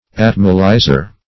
\At"mo*ly`zer\